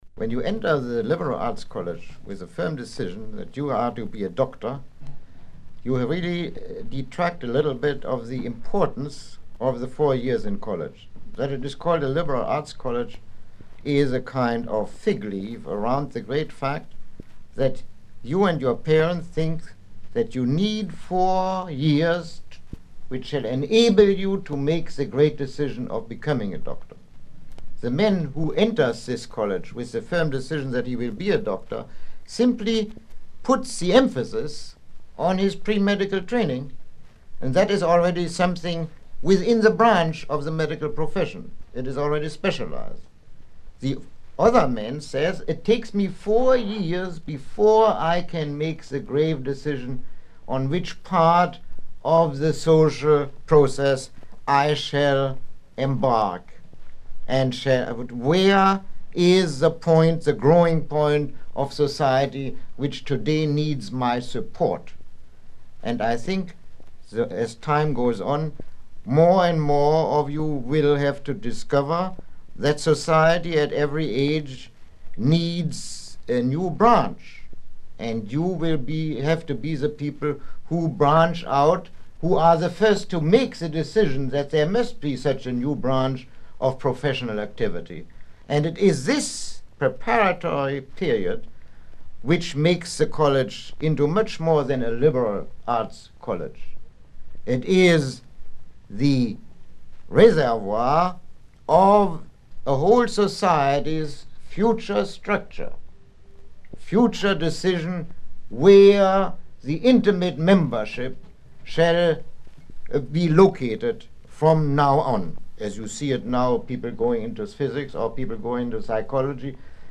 Lecture 03